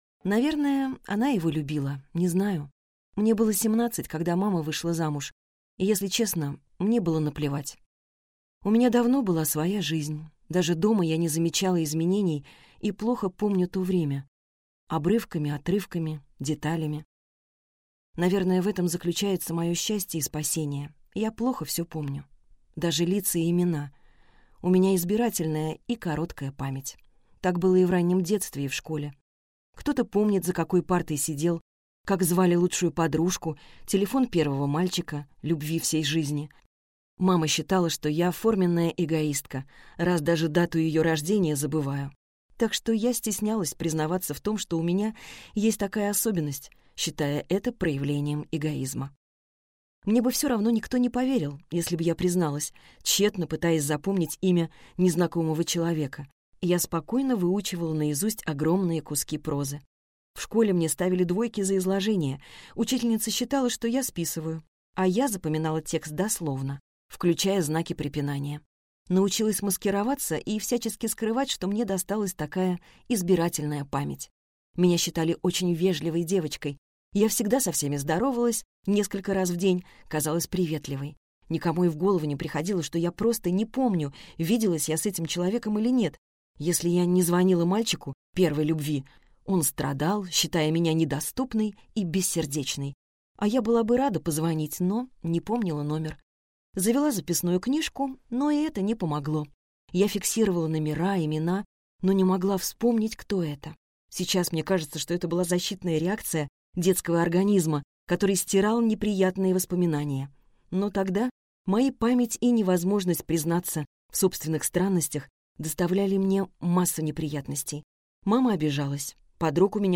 Аудиокнига Всегда кто-то платит - купить, скачать и слушать онлайн | КнигоПоиск